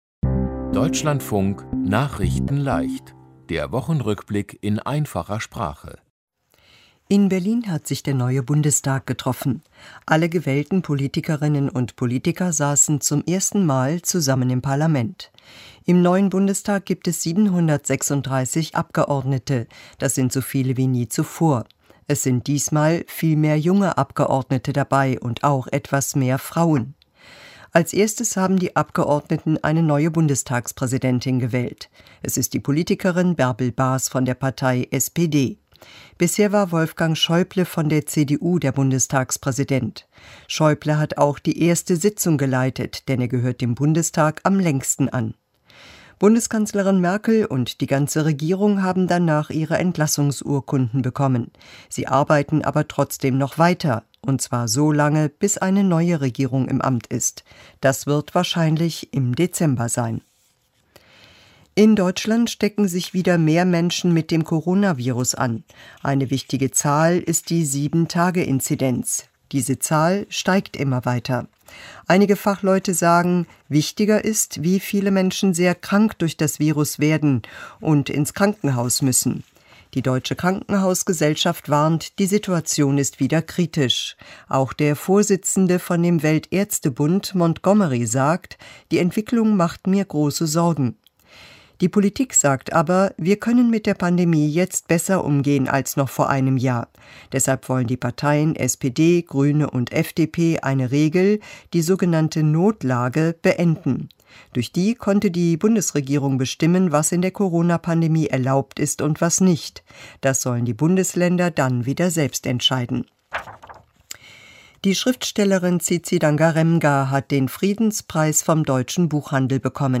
Die Themen diese Woche: Neuer Bundestag, Corona-Zahlen steigen wieder stark, Friedens-Preis, Schau-Spieler erschießt Kamera-Frau, Jugend-Wort 2021 ist "Cringe" und München raus im DFB-Pokal. nachrichtenleicht - der Wochenrückblick in einfacher Sprache.